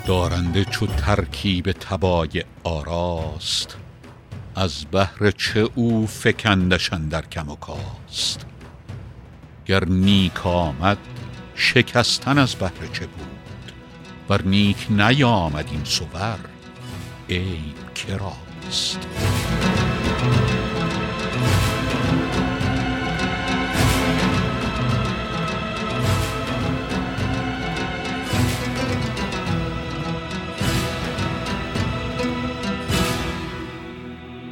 رباعی ۱۱ به خوانش فریدون فرح‌اندوز